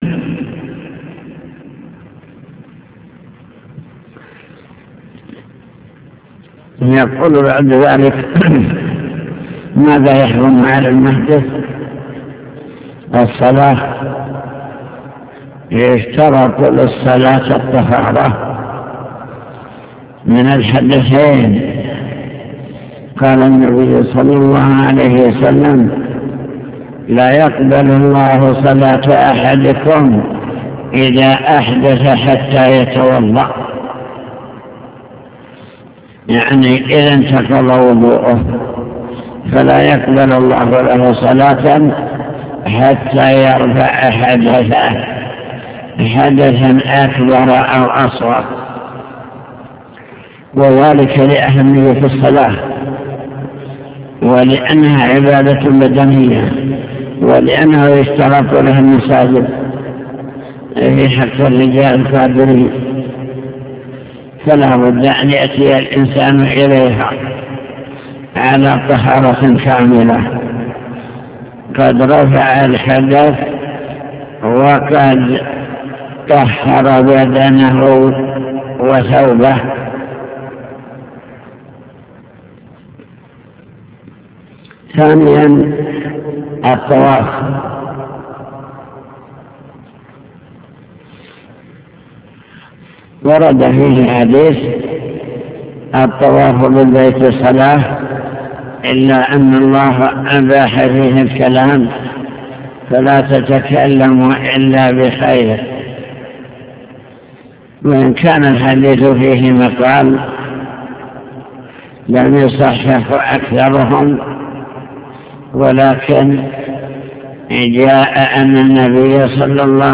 المكتبة الصوتية  تسجيلات - كتب  شرح كتاب دليل الطالب لنيل المطالب كتاب الطهارة باب الغسل